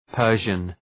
Προφορά
{‘pɜ:rʒən}